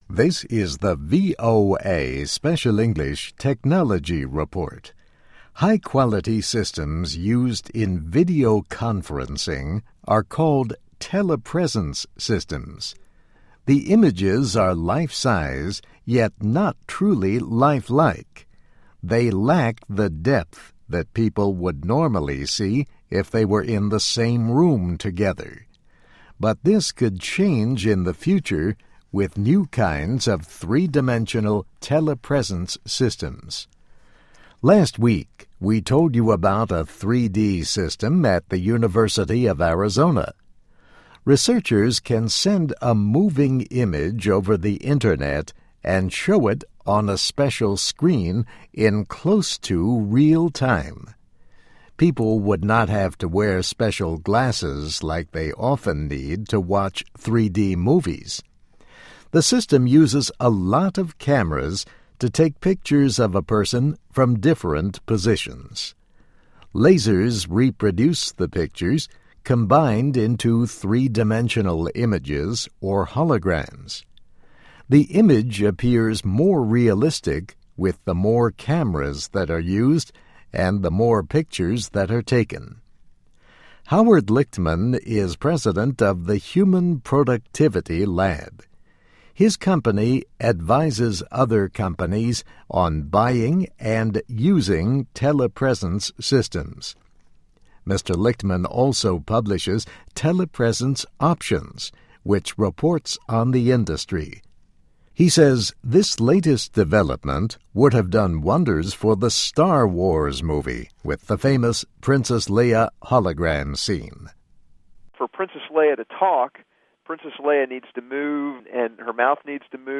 Technology Report